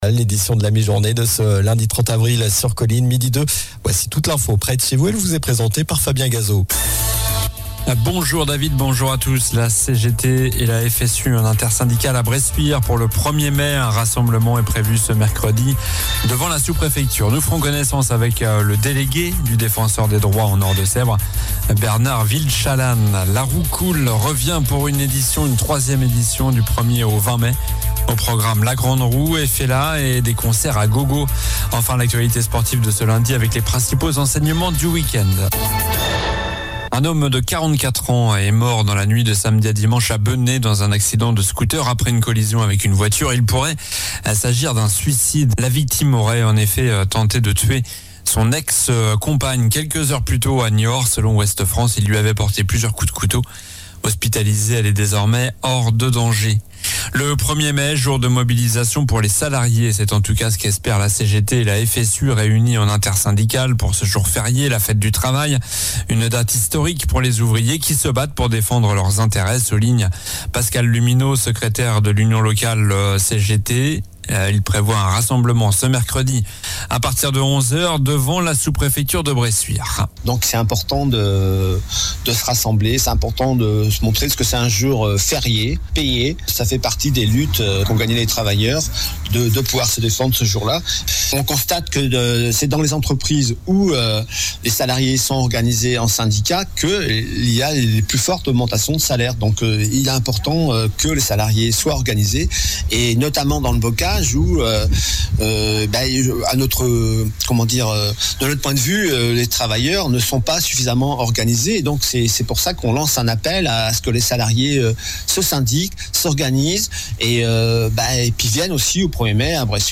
Journal du lundi 29 avril (midi)